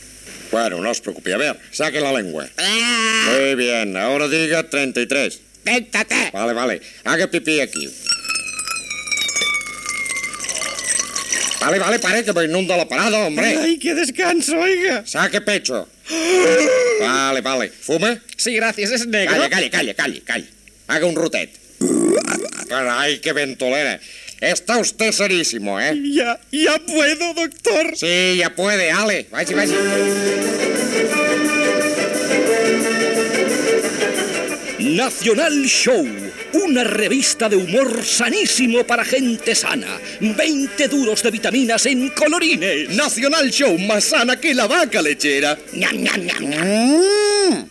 Anunci de la revista "Nacional Show".